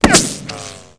sound / weapons / ric1.wav